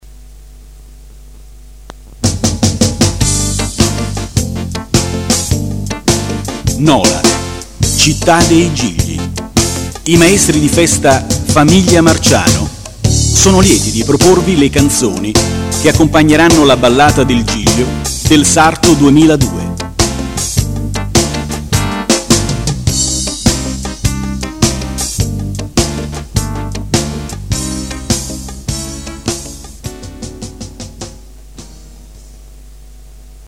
Presentazione